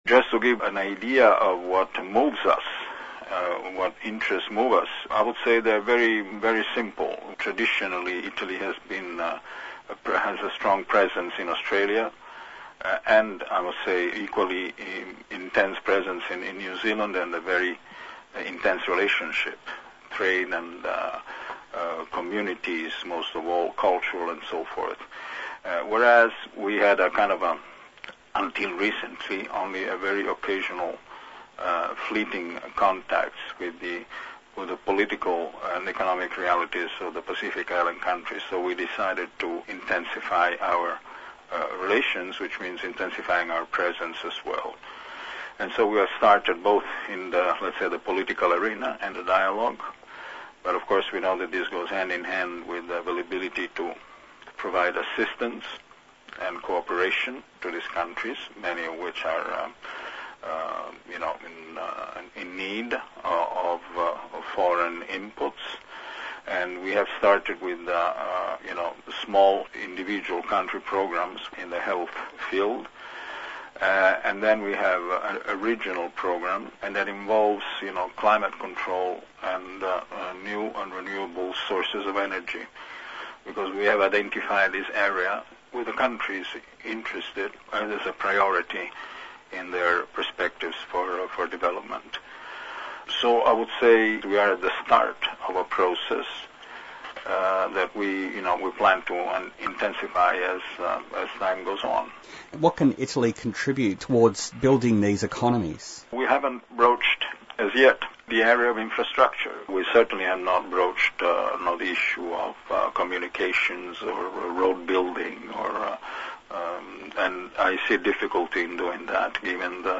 Speaker: The Italian Ambassador to Australia and the Pacific nations of Fiji, Micronesia, Nauru, Papua New Guinea, Solomon Islands and Vanuatu, Mr Stefano Starace Janfolla.